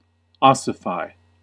Uttal
Synonymer harden Uttal US UK: IPA : /ˈɒs.ɪ.faɪ/ US: IPA : /ˈɑ.sə.faɪ/ Ordet hittades på dessa språk: engelska Ingen översättning hittades i den valda målspråket.